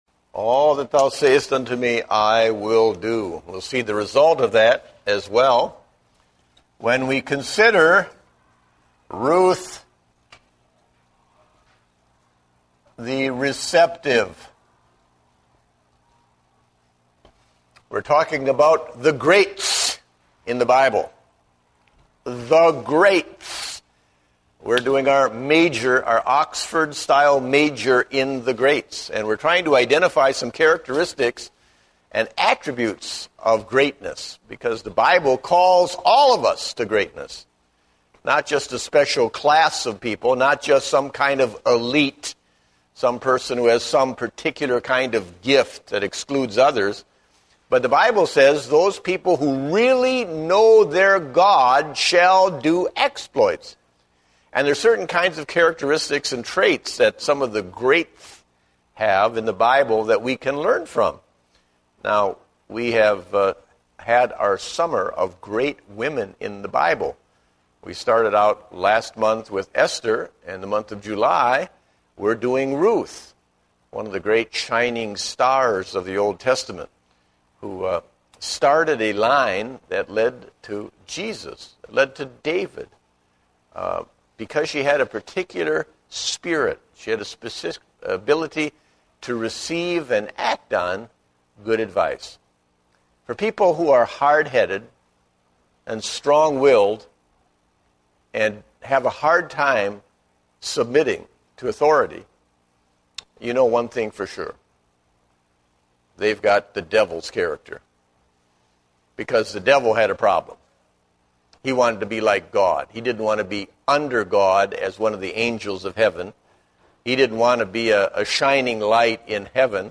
Date: July 18, 2010 (Adult Sunday School)